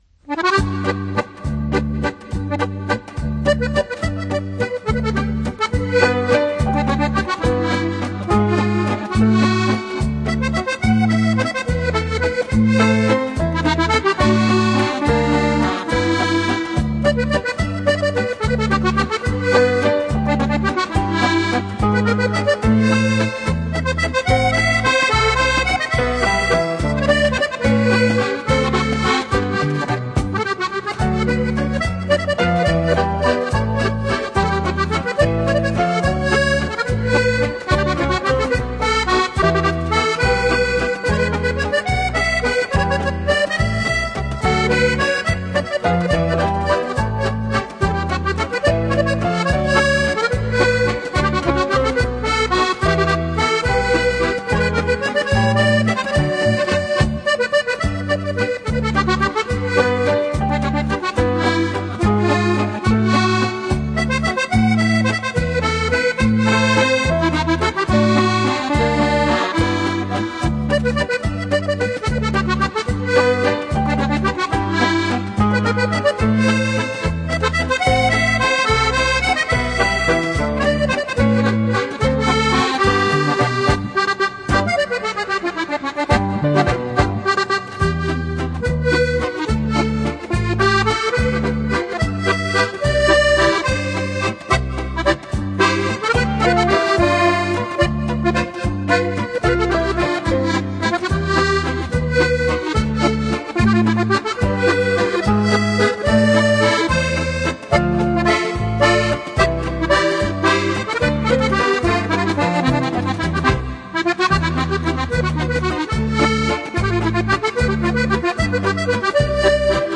Жанр: Easy Listening, Accordeon